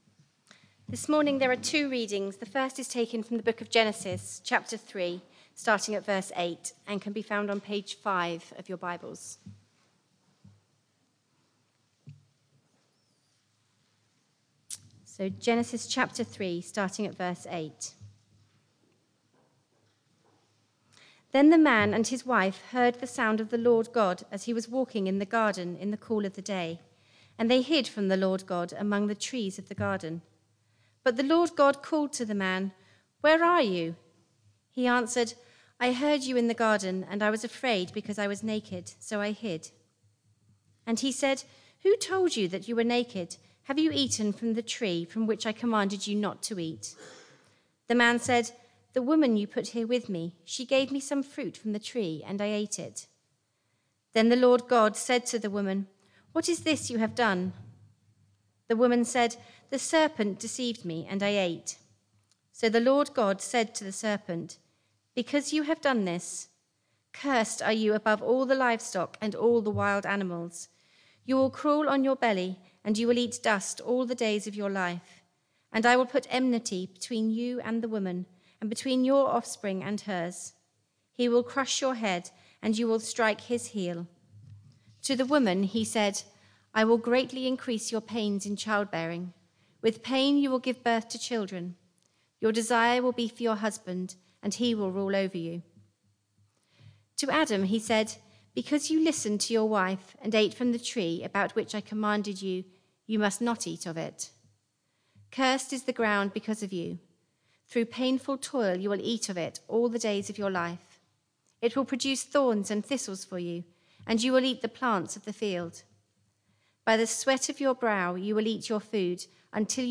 Theme: Nativity Sermon (includes a couple of sound interuptions - apologies)